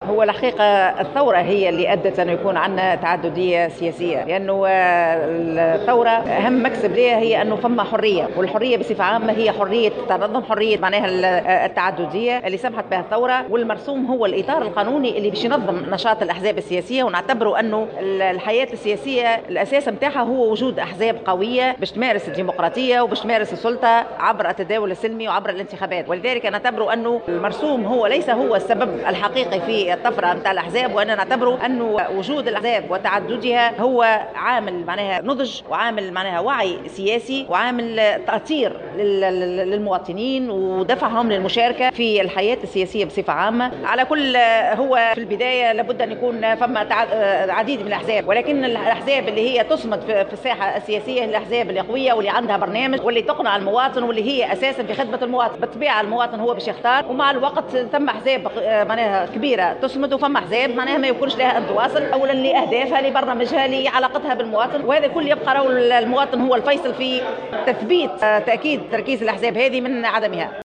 ودعت العبيدي في تصريح لمراسلة "الجوهرة أف أم " على هامش استشارة وطنية حول الإعداد لإطار قانوني جديد لتنظيم الأحزاب السياسية، إلى تفادي النقائص التي يعاني منها المرسوم القديم، خاصة فيما يتعلّق بجانب التمويل والعمل على تعديلها.